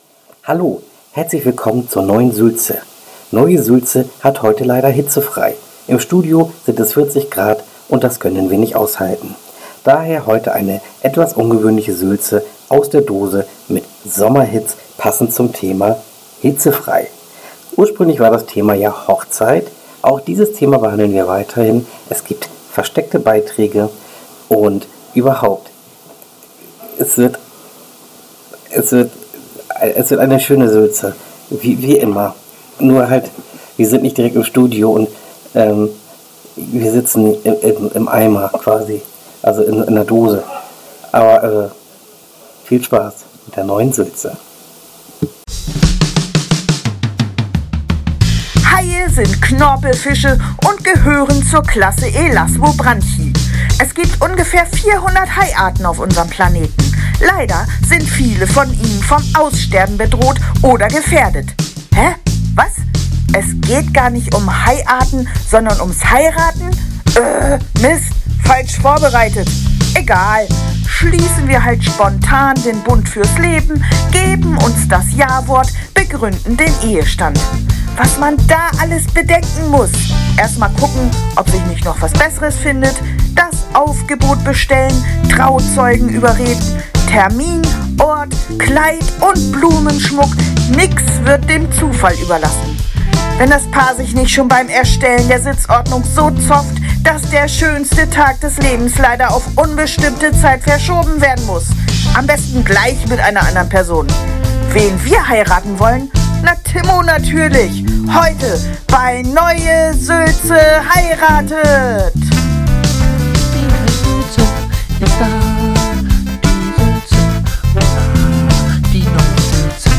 Alle 2 Wochen bietet das Team der Neue Sülze ihre Radiosendung NEUE SÜLZE als Podcast an. Sie behandeln in ihrer Show Themen wie: Getränkeunfälle, Internetsucht, den Toilettengang, Verschwörungstheorien und vieles mehr.